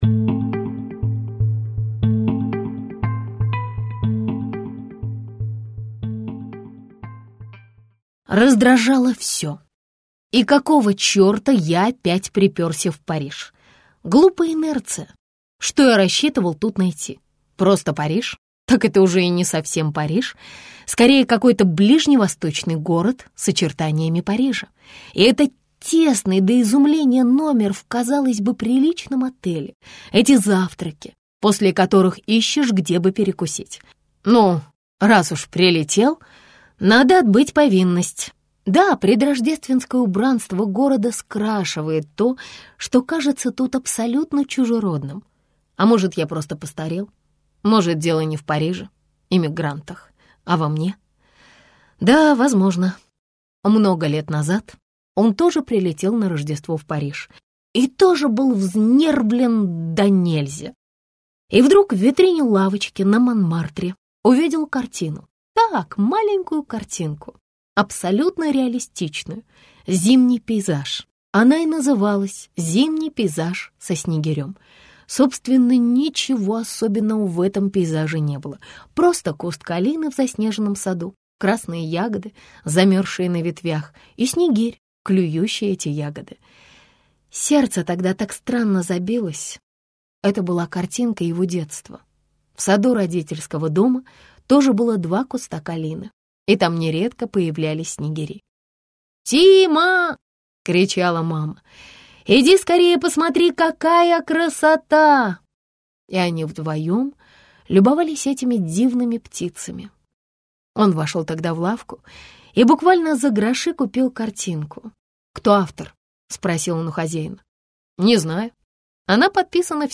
Аудиокнига Дама из сугроба - купить, скачать и слушать онлайн | КнигоПоиск